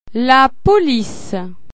La police   tawmroo-uht